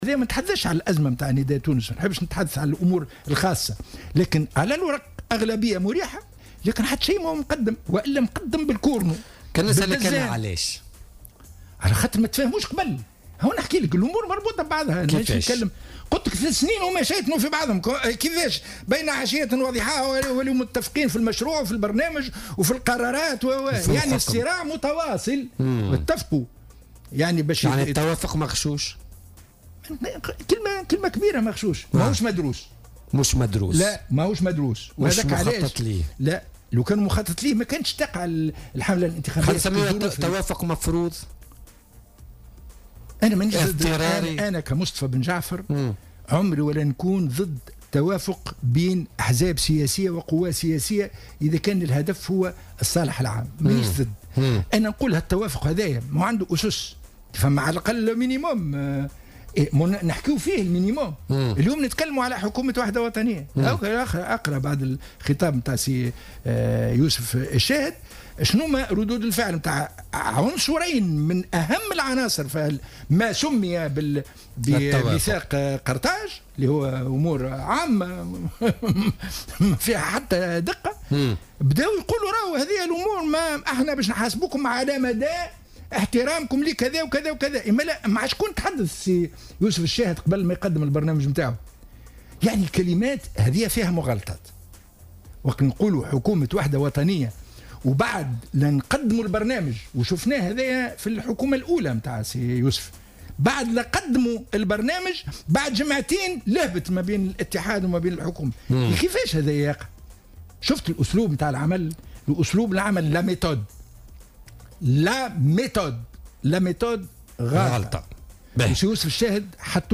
وأوضح في مداخلة له اليوم في برنامج "بوليتيكا" إن الحديث عن حكومة الوحدة الوطنية فيه الكثير من المغالطات.